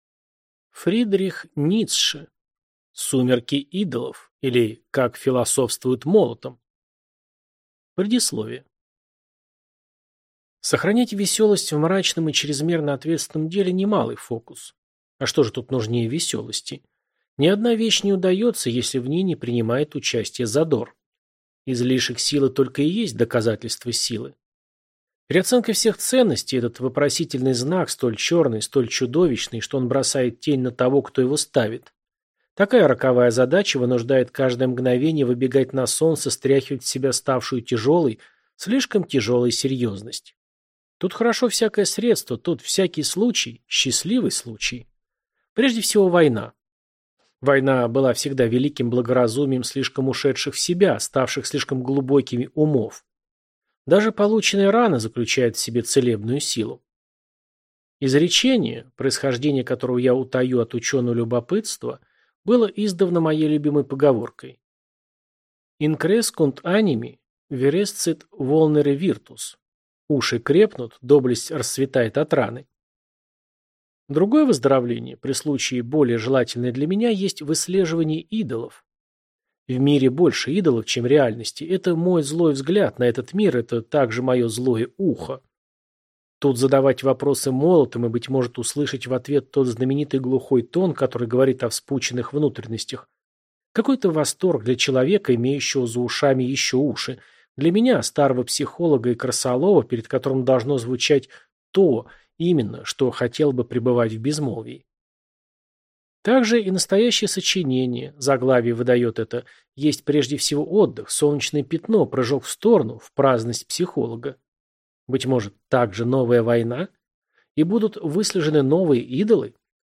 Аудиокнига Сумерки идолов | Библиотека аудиокниг